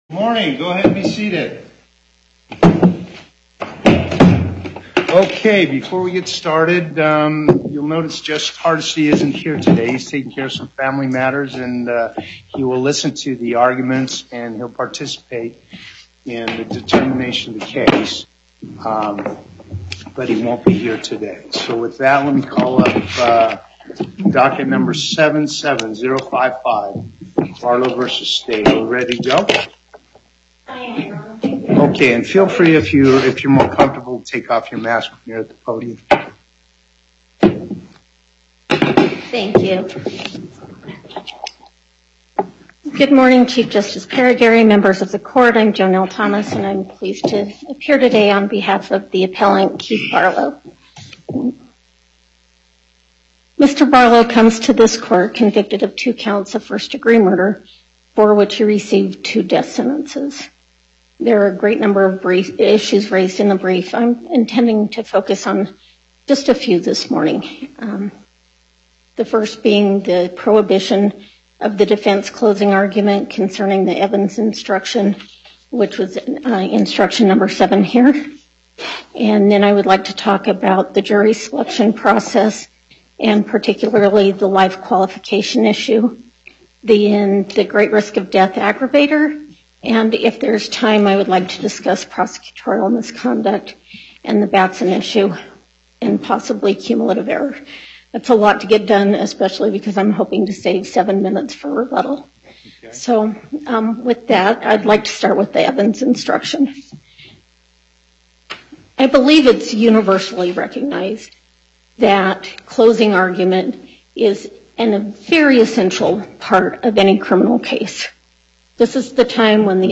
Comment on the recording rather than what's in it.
Before the En Banc Court